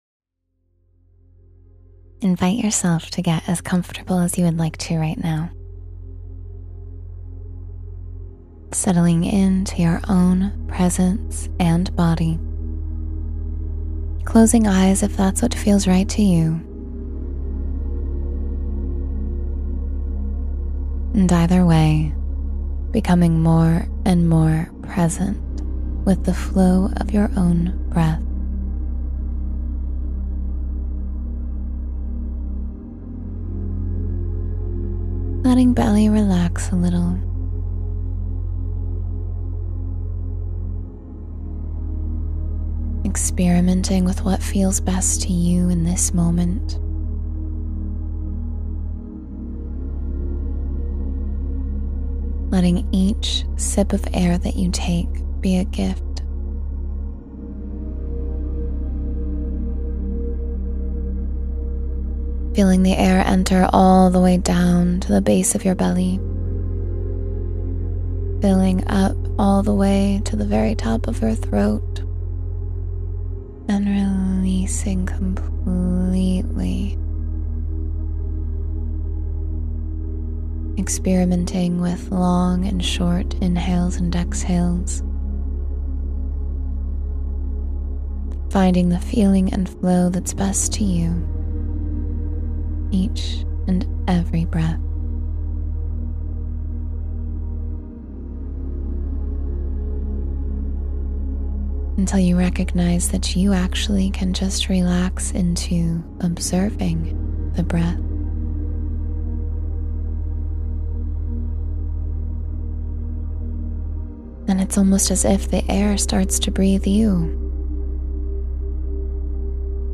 Ten Minutes to Pure Inner Peace — Guided Meditation for Calm and Centered Focus